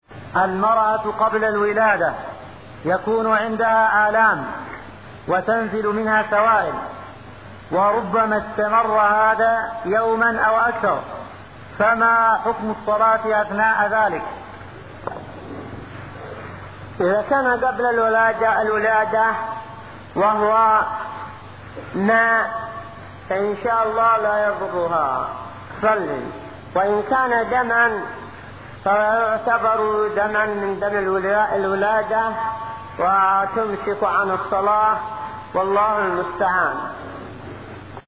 muqbel-fatwa2594.mp3